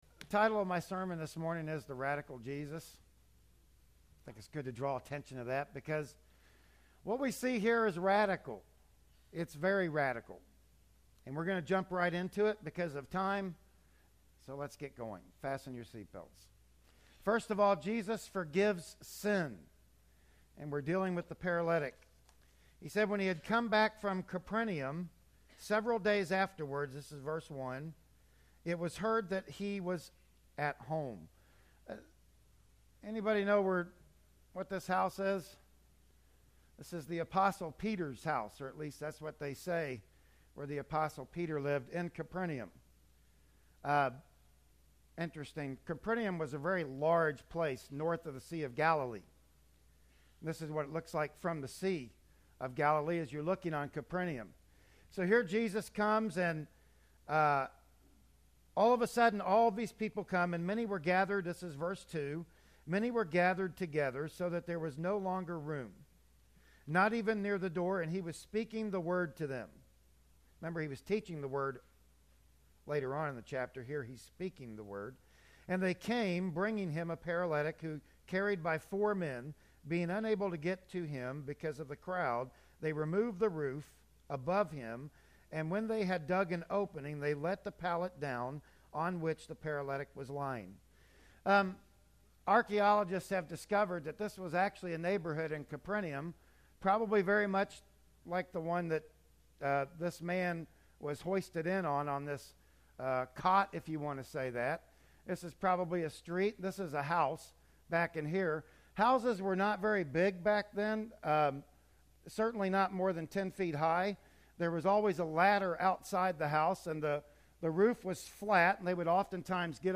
"Mark 2:1-28" Service Type: Sunday Morning Worship Service Bible Text